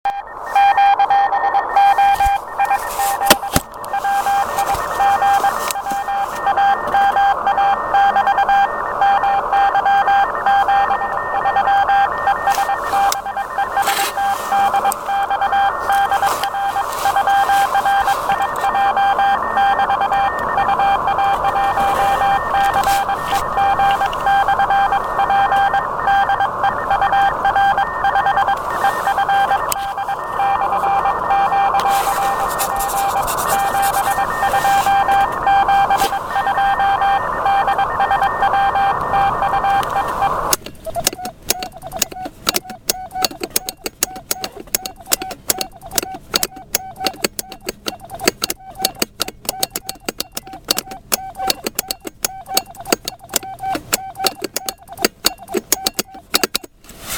И немного аудио, приём на Р-143.